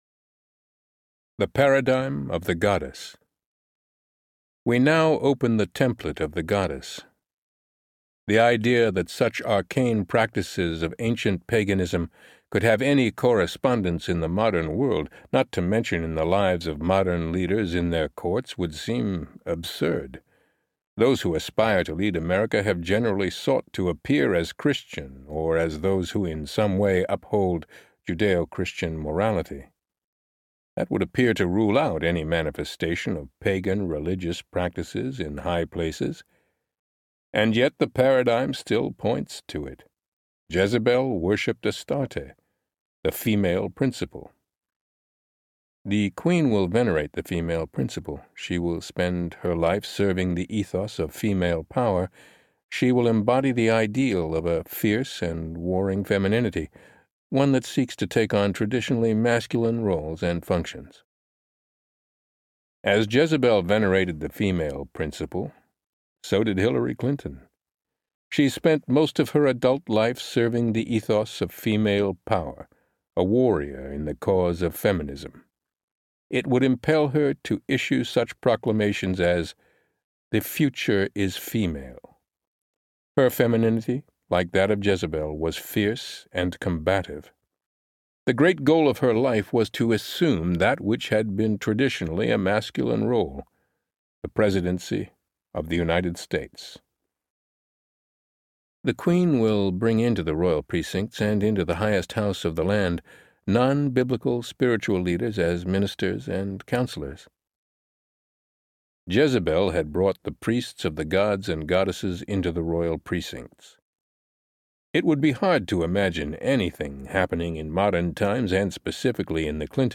The Paradigm Audiobook
9.33 Hrs. – Unabridged